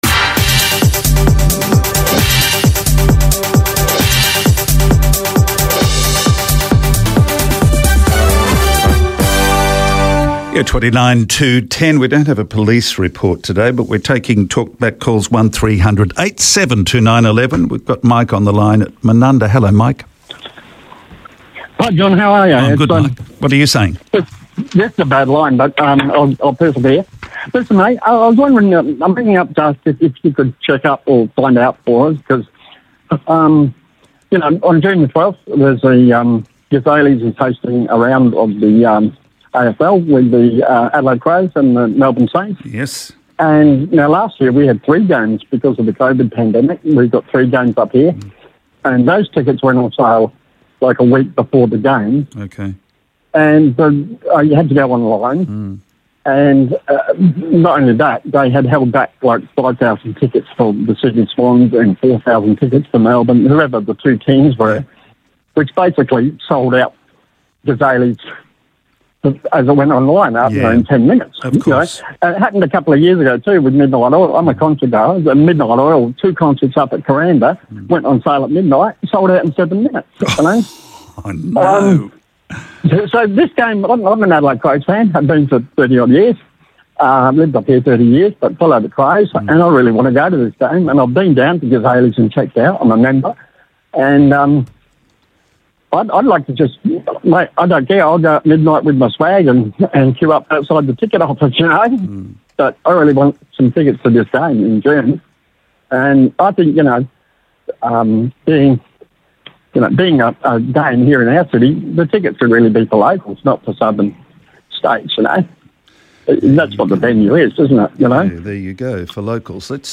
talkback